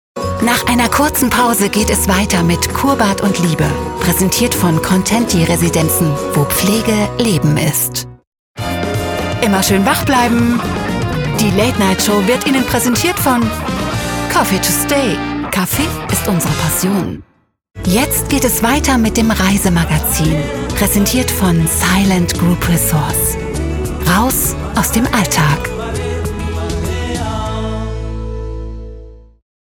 Erfahrene Profisprecherin
Trailer Voice
1.-TV-Patronate-Trailerstimme.mp3